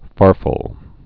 (färfəl)